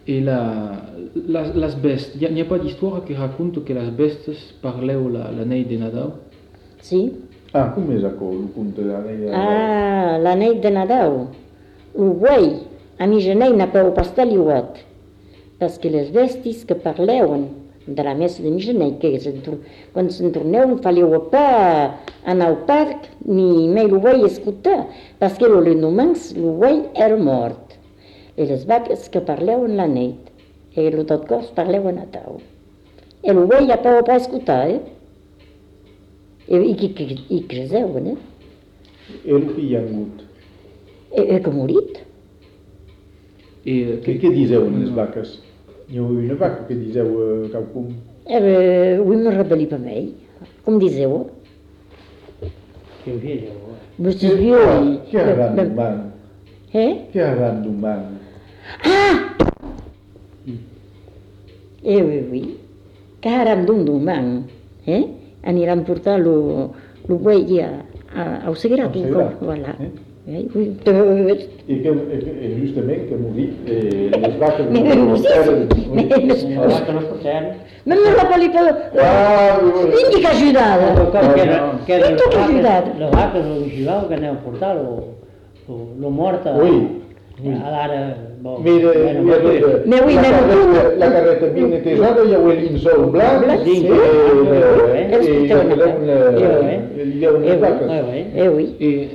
Lieu : Cazalis
Genre : conte-légende-récit
Effectif : 1
Type de voix : voix de femme
Production du son : parlé